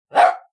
Single Dog Bark (king Charles Spaniel) Téléchargement d'Effet Sonore